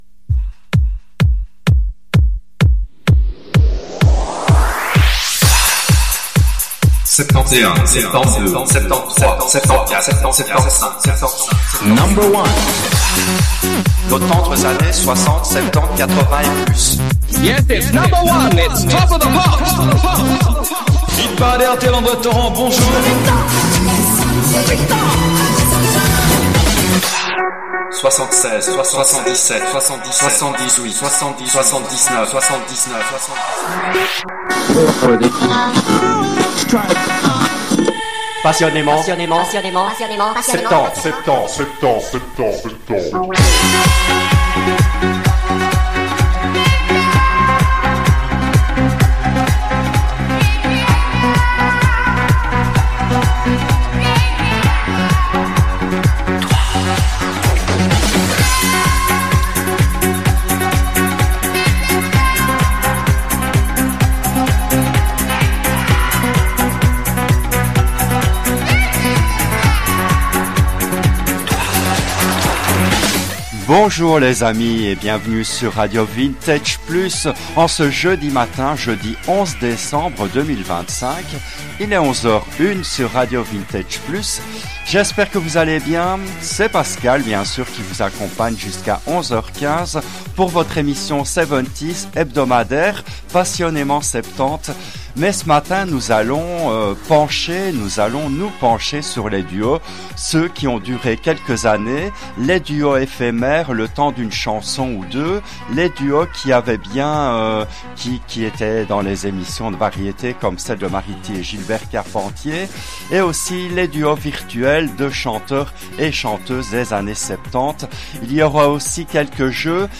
Ils chantent des mélodies inédites à découvrir ou des tubes, mais allez-vous deviner qui chante avec qui?